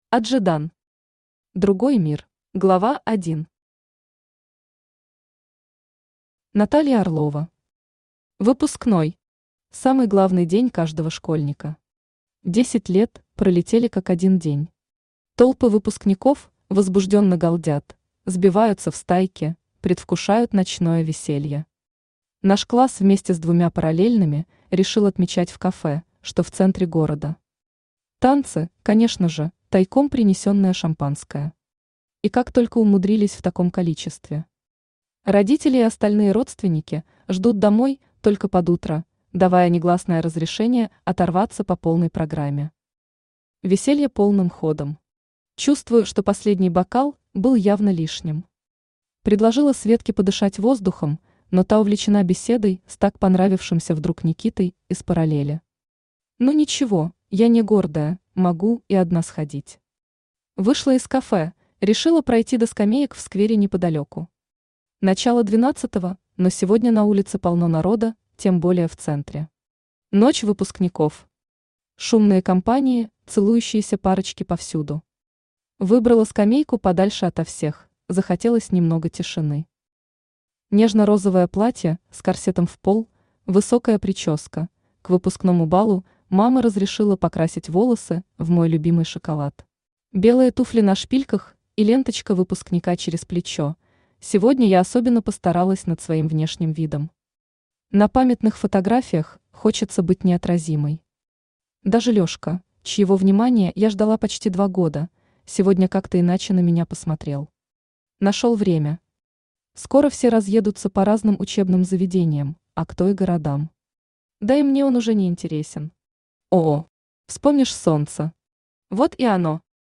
Аудиокнига Другой мир | Библиотека аудиокниг
Aудиокнига Другой мир Автор Аджедан Читает аудиокнигу Авточтец ЛитРес.